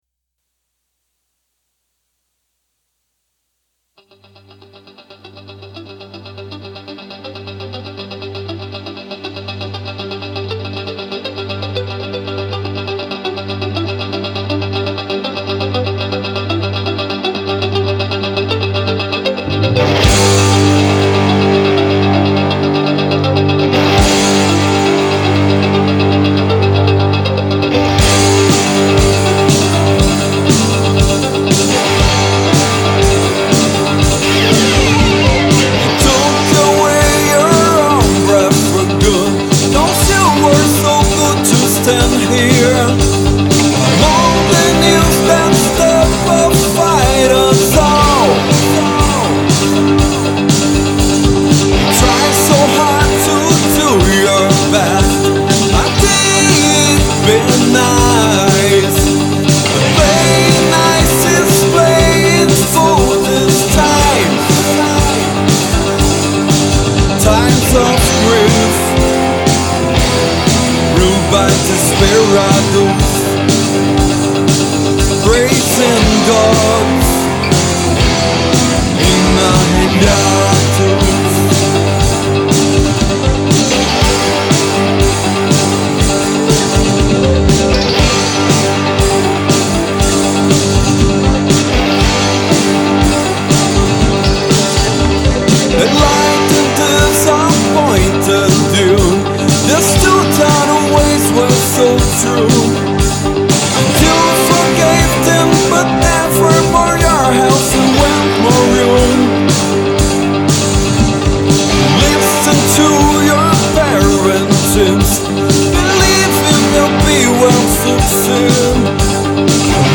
EstiloRock Progressivo